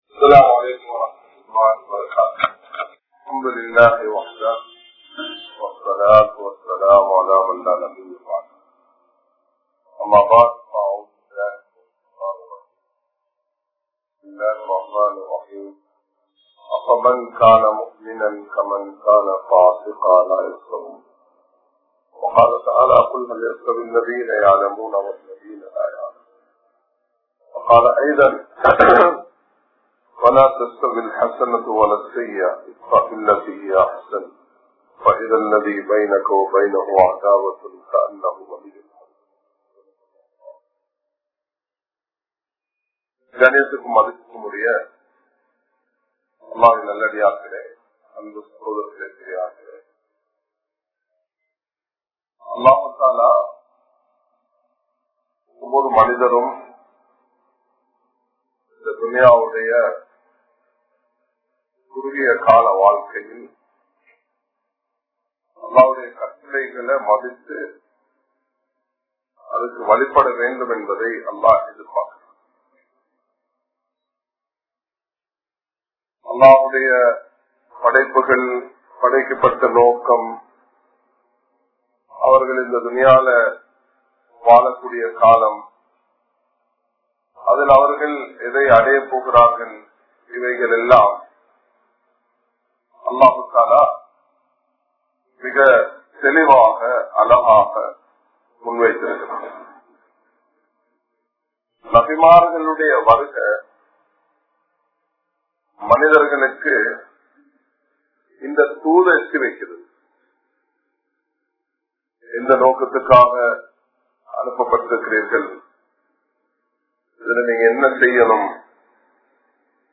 Deenudaiya Ulaippu Mikap Periya Selvam(தீனுடைய உழைப்பு மிகப் பெரிய செல்வம்) | Audio Bayans | All Ceylon Muslim Youth Community | Addalaichenai
Dehiwela, Kawdana Road Jumua Masjidh